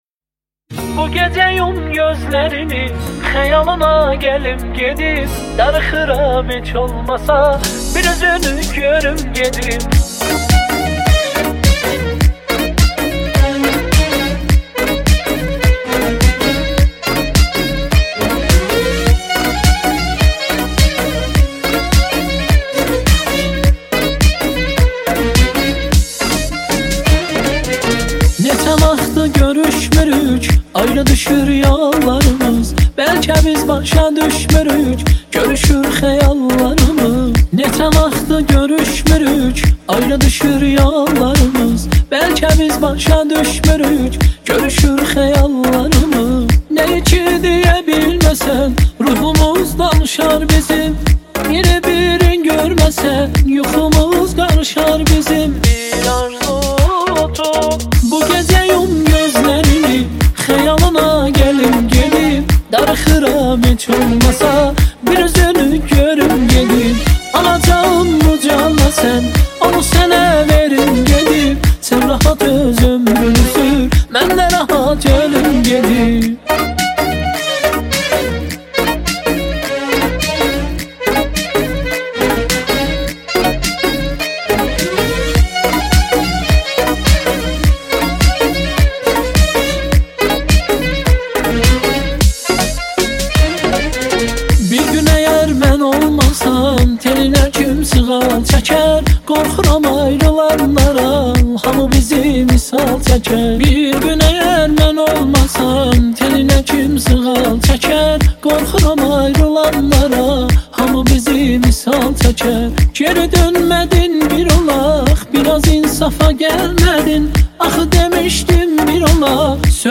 اهنگ ترکی